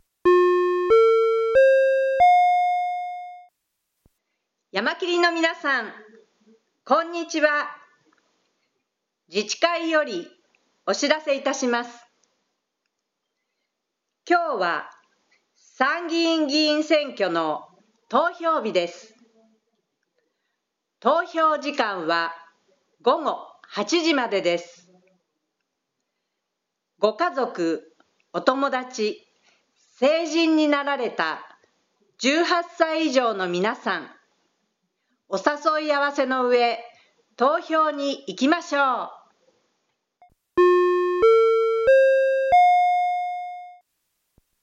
Jul 20, 2025　「投票所へ行こう！」放送実施…西谷津甘夏終了
朝7時から参議院選挙の投票が始まった。